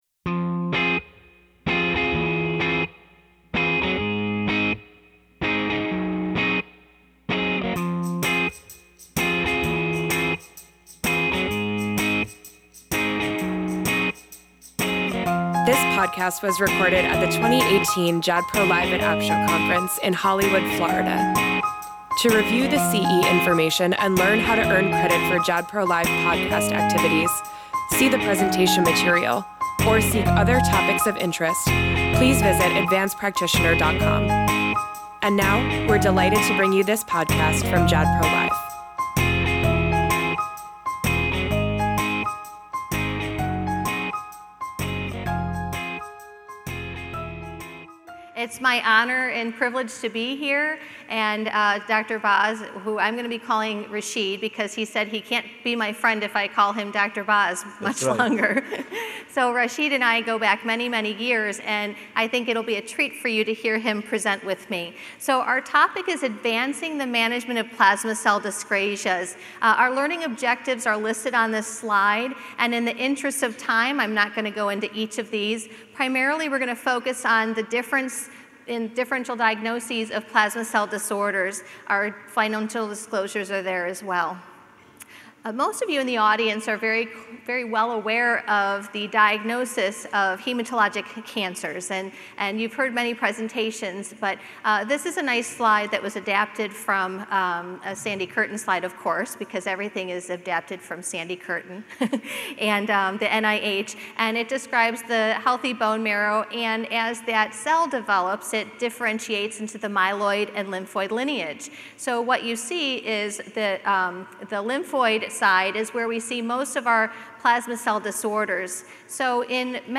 Through expert, case-based discussion, learn how to interpret differential diagnoses of plasma cell dyscrasias, implement the recent changes in the clinical management of the spectrum of multiple myeloma, evaluate the risks and benefits of early treatments for smoldering myeloma, and plan management strategies for patients with solitary plasmacytomas and amyloidosis.